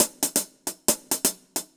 UHH_AcoustiHatB_135-05.wav